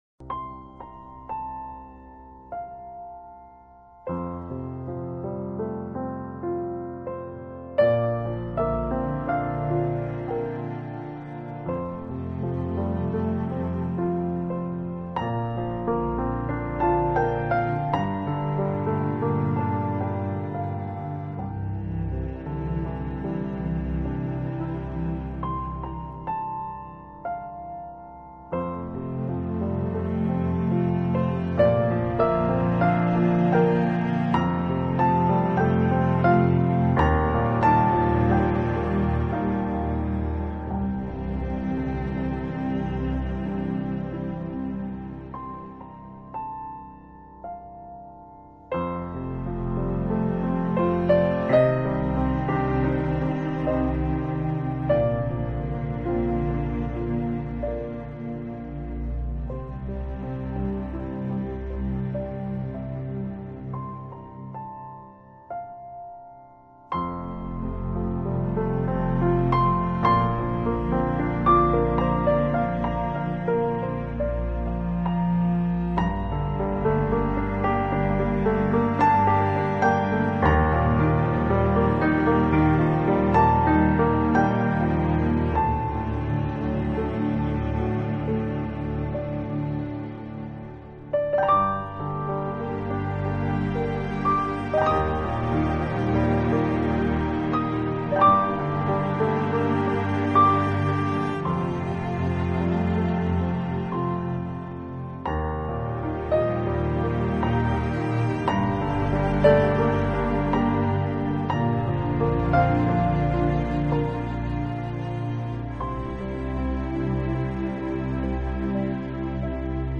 【纯美钢琴】
音乐类型：轻音乐,器乐
清澈灵透的钢琴为基调，配上丰富优美的管弦乐，并融合完美的世界节奏和爵士乐，确切